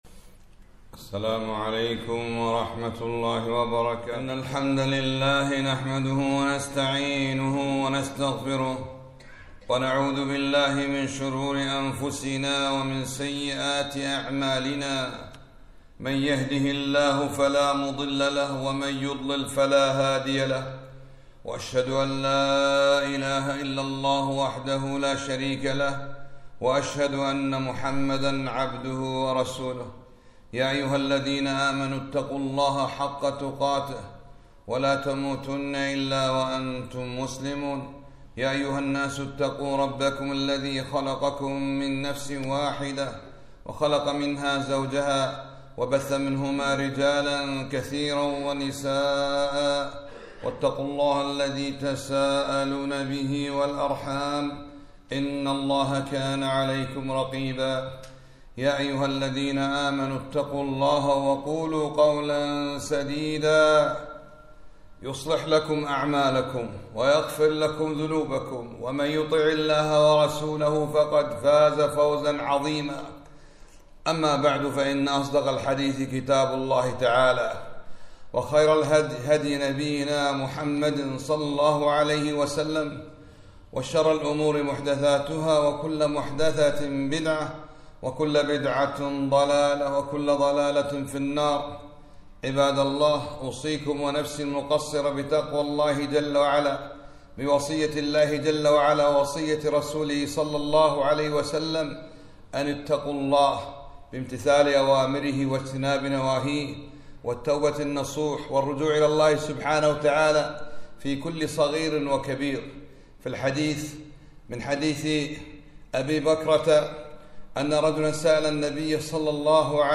خطبة - ( رغم أنفُ عبدٍ دخل عليه رمضان فلم يغفر له )